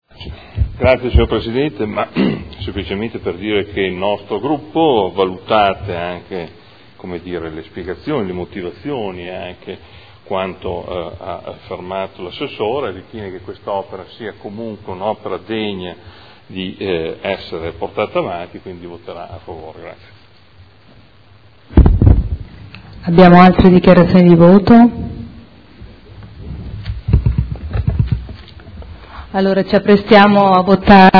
Seduta del 18/06/2012. Dichiarazione di voto su proposta di deliberazione.